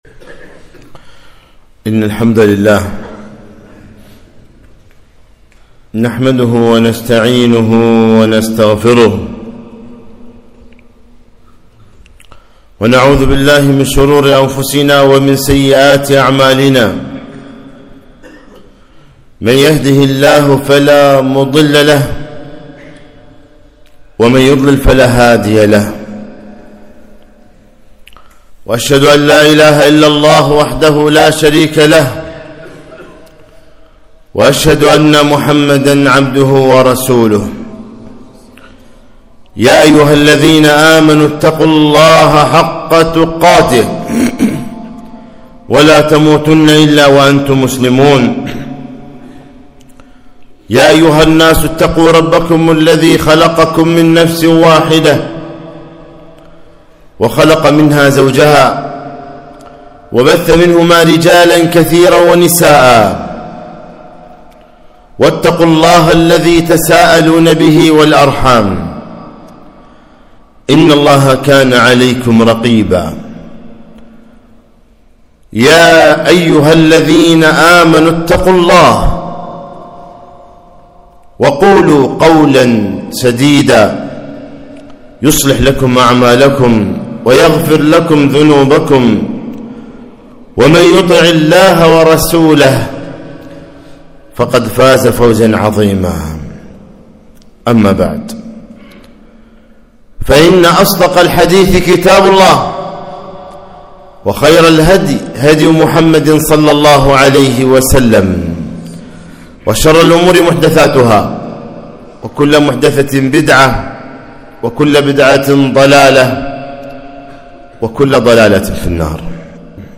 خطبة - (احذر أن تكون مؤذيا)